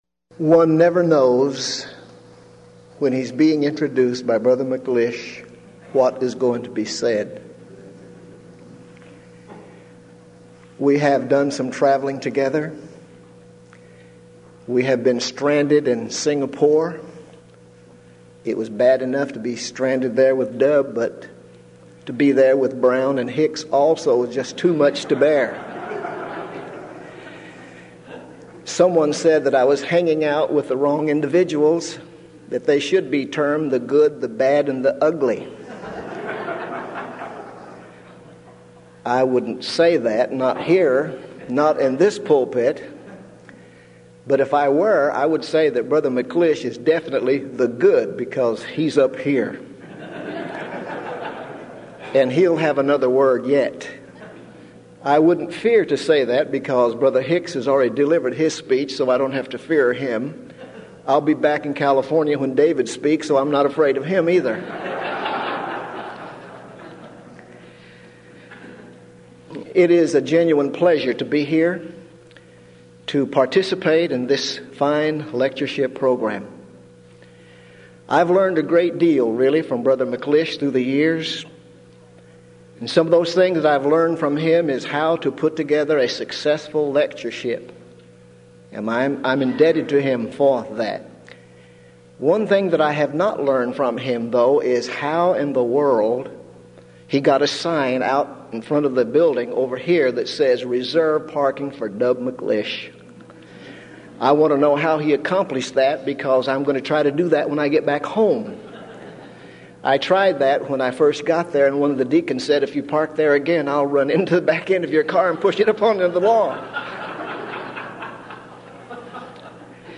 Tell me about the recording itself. Series: Denton Lectures Event: 16th Annual Denton Lectures Theme/Title: Studies In Ephesians